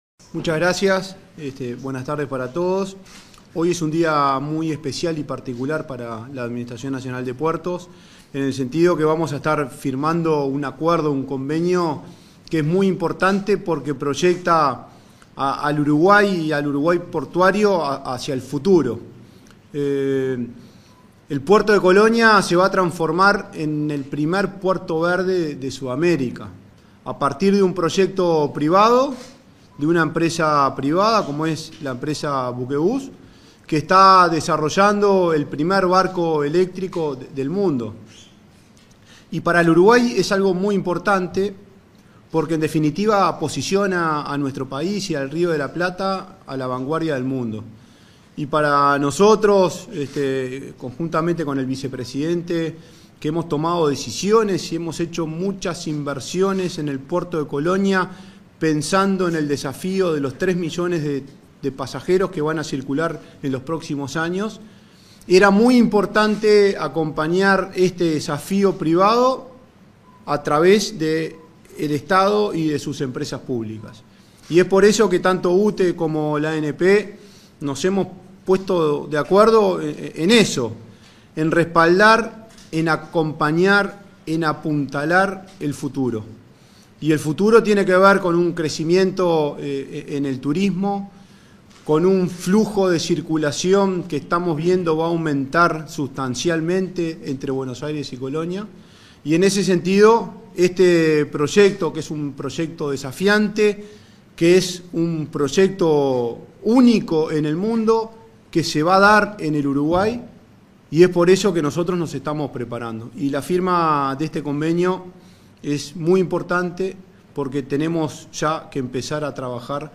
Palabras de la presidenta de UTE y del presidente de la ANP
Palabras de la presidenta de UTE y del presidente de la ANP 22/11/2024 Compartir Facebook X Copiar enlace WhatsApp LinkedIn En el marco de la firma de un convenio para realizar adaptaciones en el puerto de Colonia para el ingreso de buques eléctricos, se expresaron la presidenta de UTE, Silvia Emaldi, y el presidente de la Administración Nacional de Puertos(ANP), Juan Curbelo.